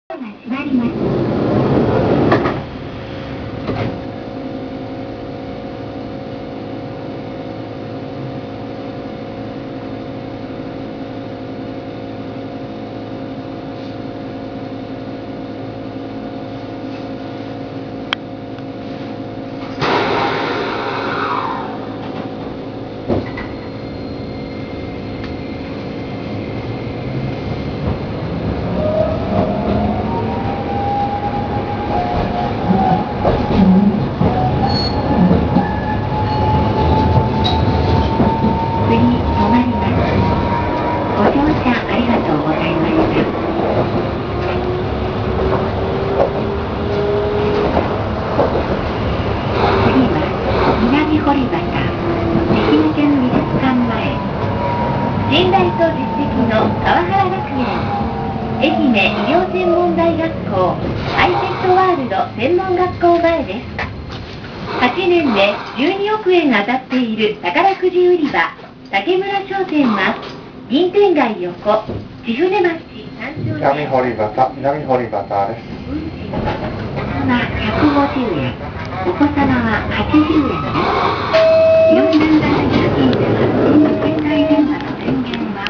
〜車両の音〜
・2100形走行音
【城南線】市役所前→南堀端（1分26秒：466KB）…2107にて
さして路面電車では珍しくもなんともない東洋IGBTです。やかましすぎるツリカケと無難すぎる音。
2107_shiyakushomae-minamihoribata.WAV